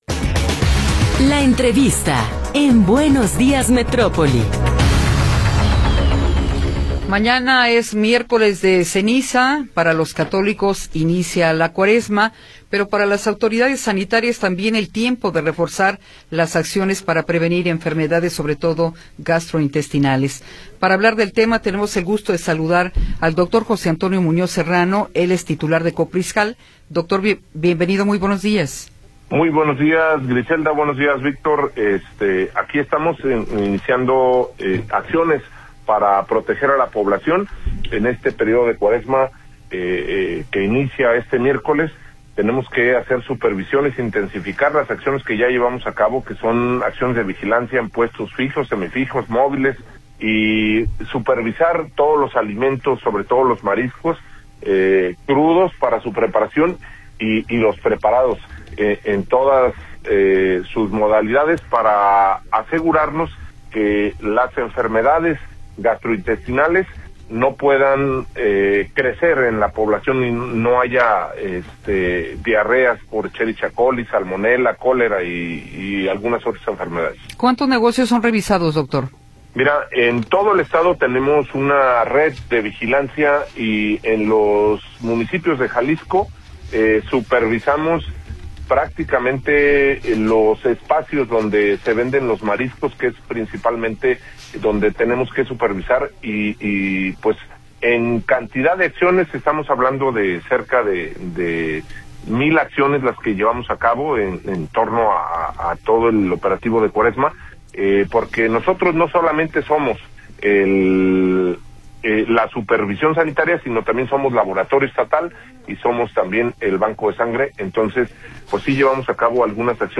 Entrevista con José Antonio Muñoz Serrano